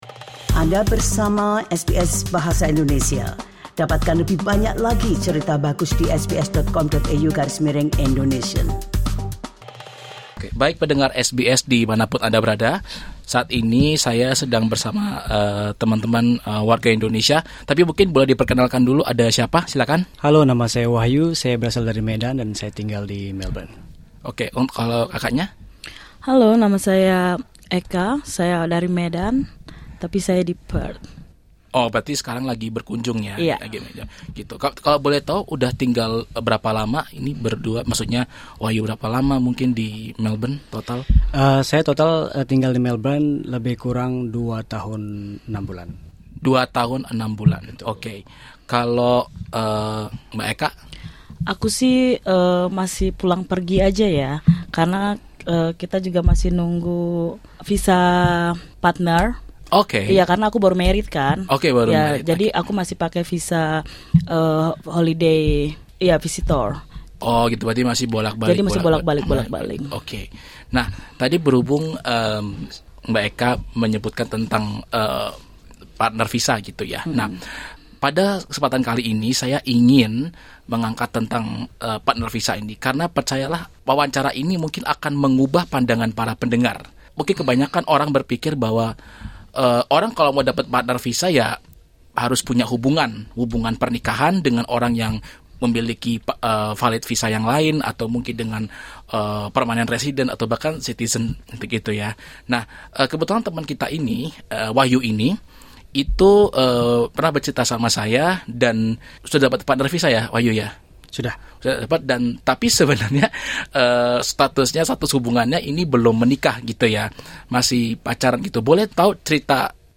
Disclaimer Informasi yang disampaikan dalam wawancara ini bersifat umum.